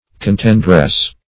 Contendress \Con*tend"ress\, n.